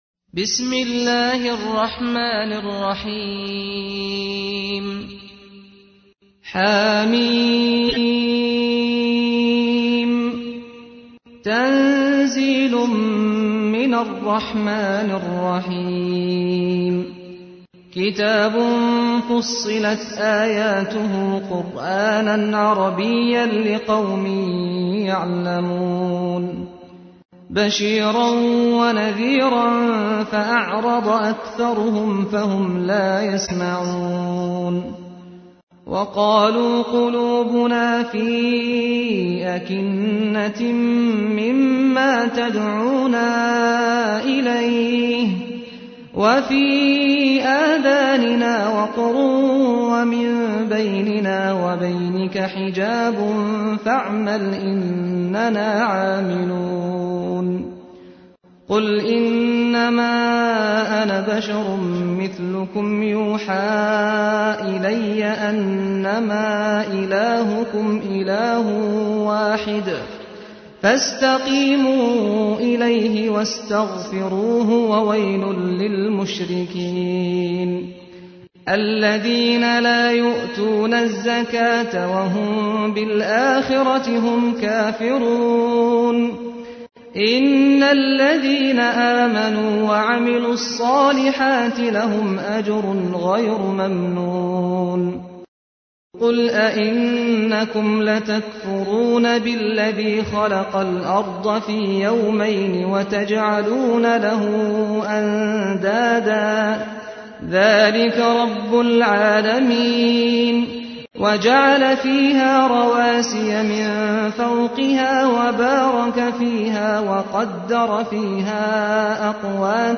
تحميل : 41. سورة فصلت / القارئ سعد الغامدي / القرآن الكريم / موقع يا حسين